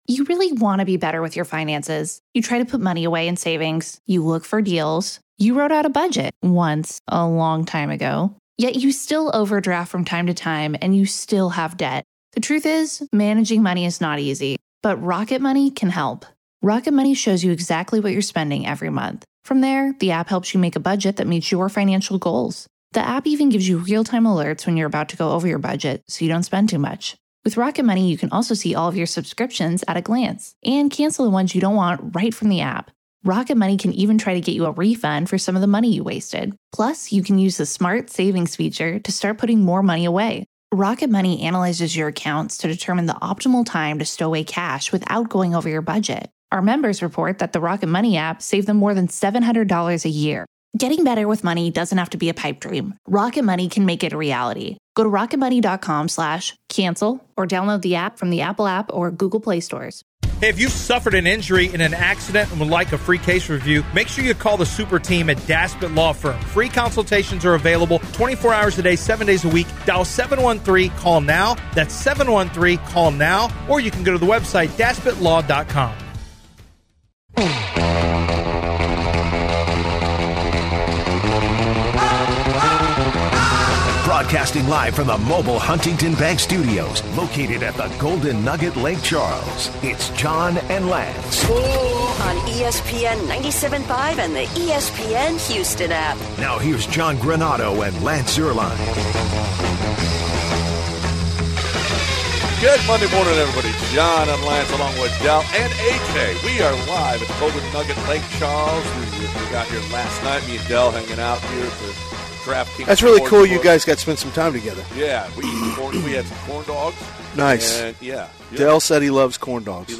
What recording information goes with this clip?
Live from the Golden Nugget in Lake Charles